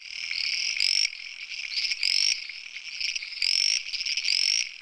frog2.wav